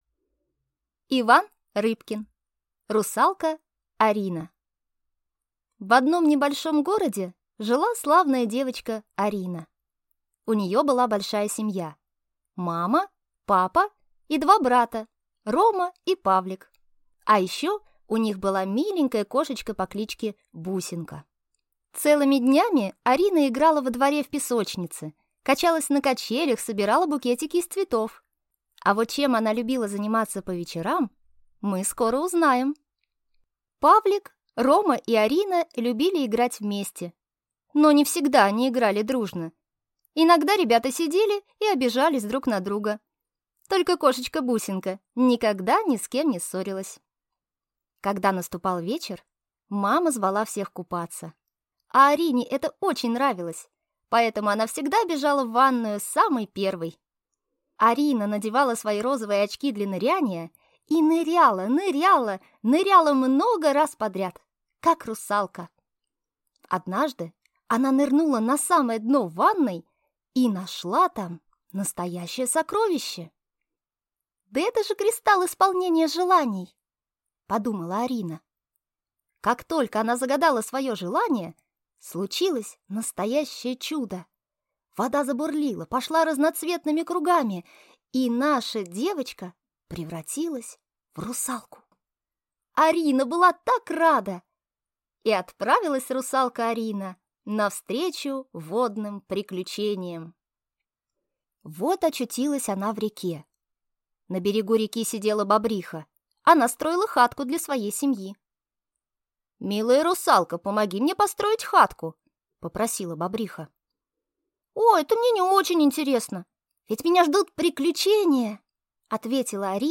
Аудиокнига Русалка Арина | Библиотека аудиокниг